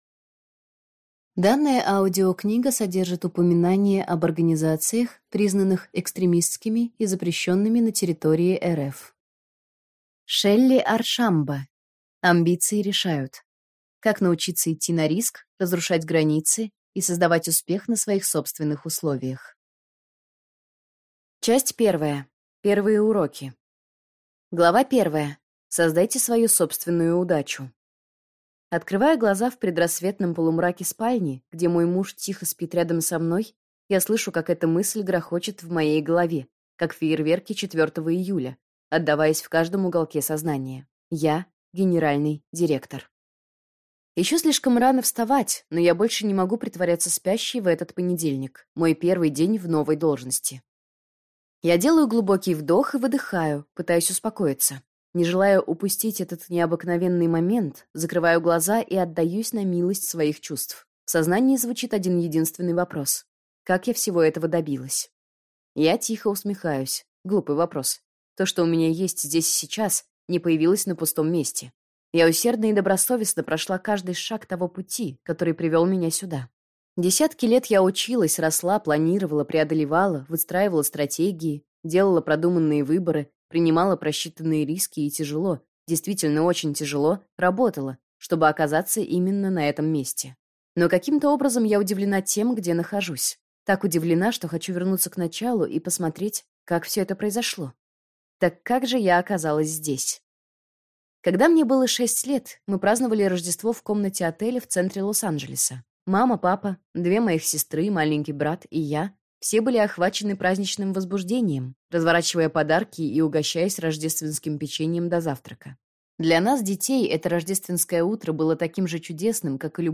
Аудиокнига Амбиции решают. Как научиться идти на риск, разрушать границы и создавать успех на своих собственных условиях | Библиотека аудиокниг